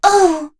Kara-Vox_Damage_03.wav